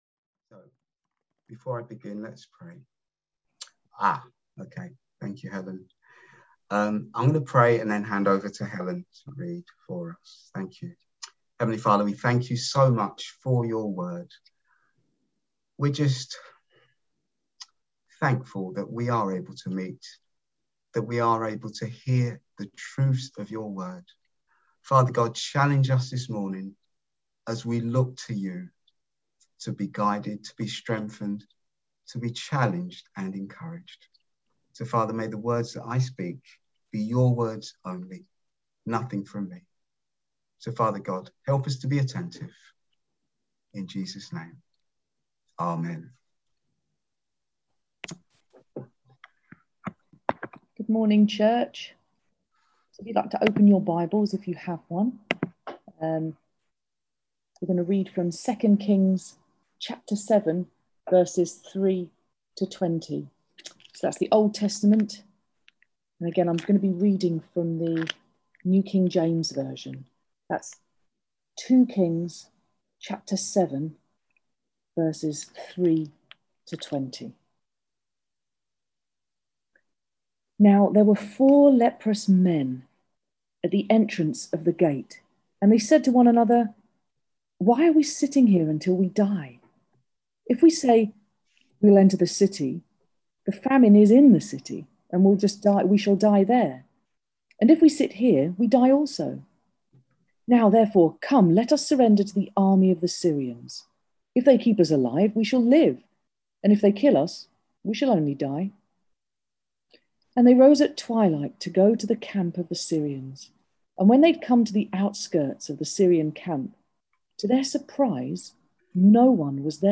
None the less we are finding ways to ensure we can still share God’s Word and have fellowship online.
Below is the recording of the sermon for this week.